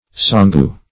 Search Result for " sangu" : The Collaborative International Dictionary of English v.0.48: Sanga \San"ga\, Sangu \San"gu\, n. (Zool.)